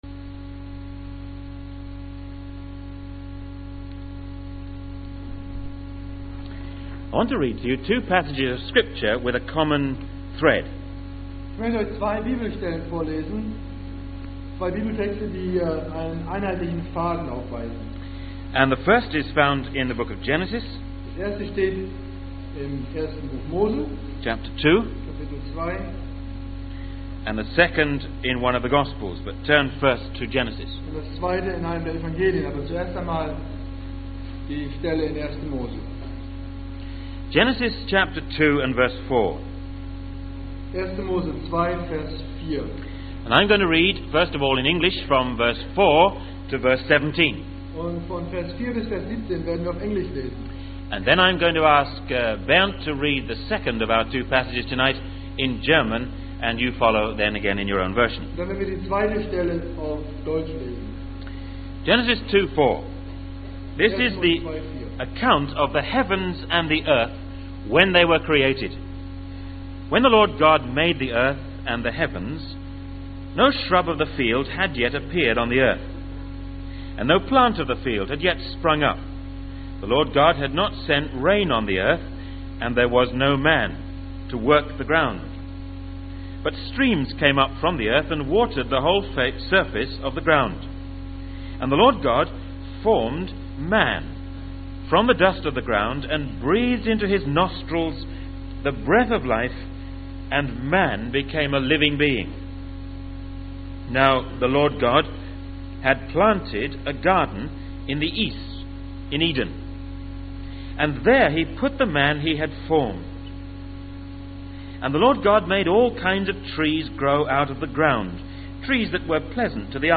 In this sermon, the speaker shares a personal experience of witnessing a young man giving his testimony for the first time in front of a large audience. The speaker emphasizes the importance of reacting positively to the challenges and trials in life, as God is working to cultivate and produce a fruitful life in us.